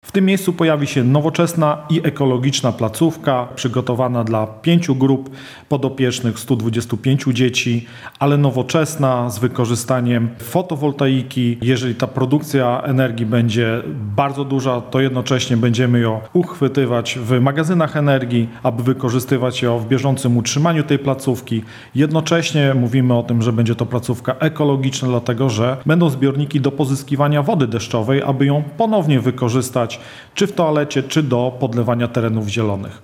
– I tak przetrwał pół wieku – mówi burmistrz Łęcznej Leszek Włodarski.